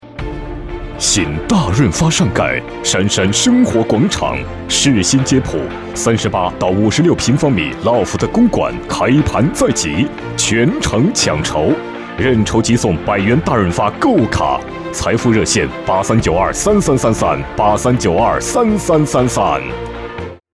A男18号 | 声腾文化传媒
【广告】新大润发男18-大气浑厚.mp3